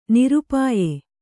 ♪ nirupāye